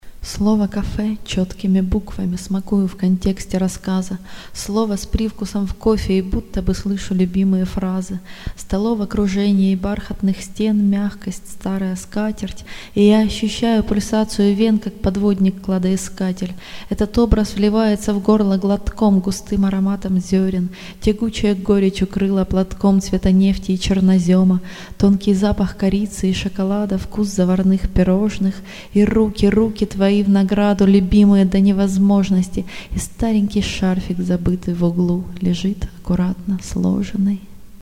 Декламация (2610)